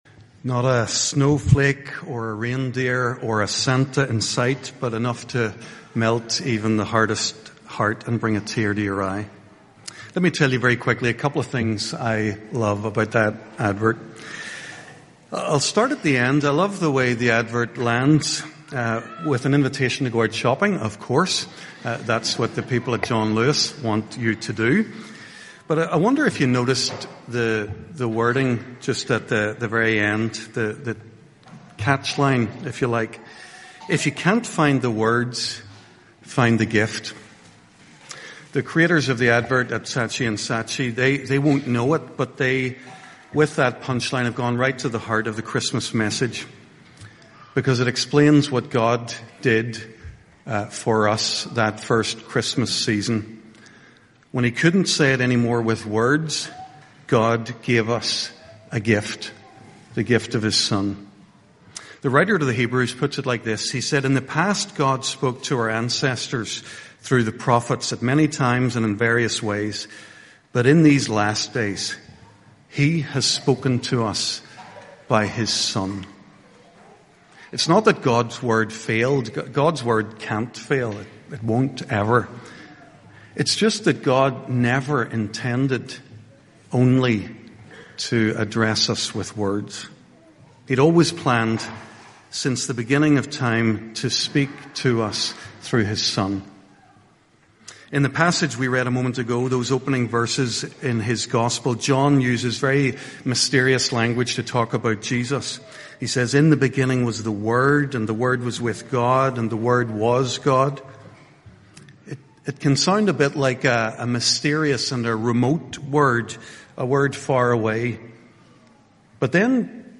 Christmas Day Service 2025
HRPC-Christmas-Morning-Service-Sermon-2025.mp3